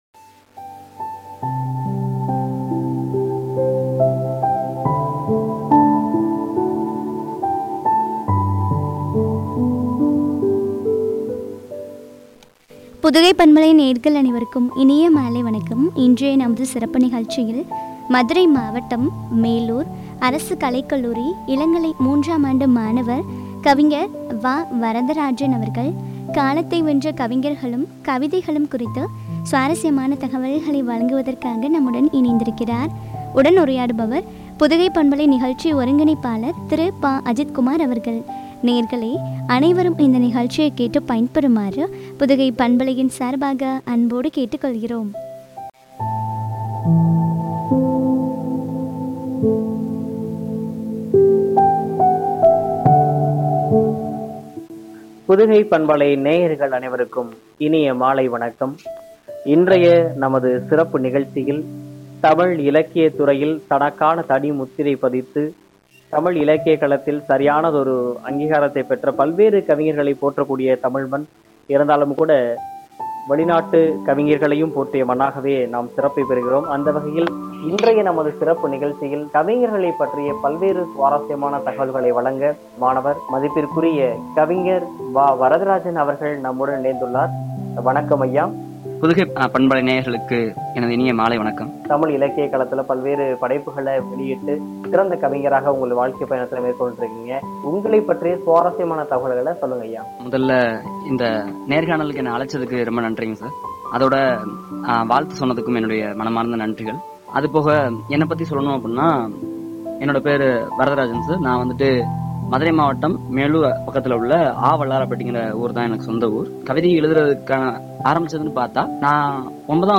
கவிதைகளும் குறித்து வழங்கிய உரையாடல்.